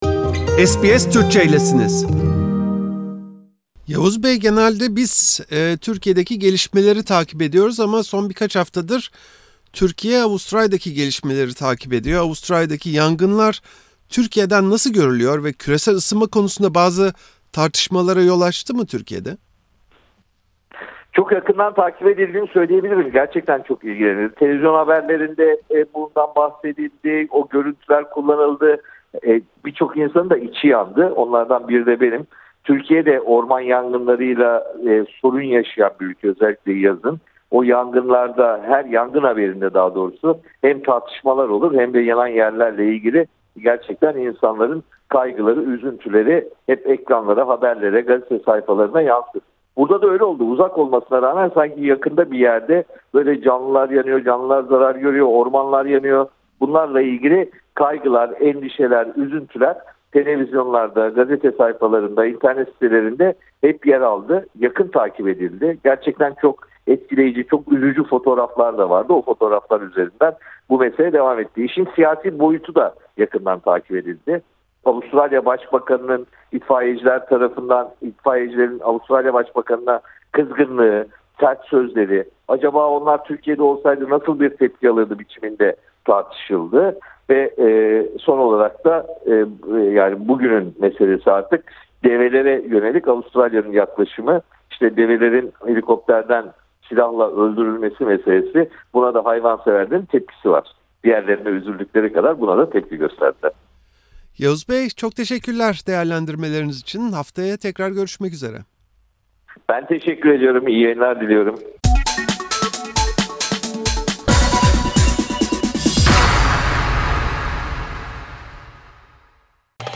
SBS Türkçe'ye konuşan gazeteci Yavuz Oğhan, Avustralya'da devam eden yangınların Türkiye'de yakından takip edildiğini ancak helikopterlerden vurulan develerinde en az yangınlar kadar üzdüğünü söyledi.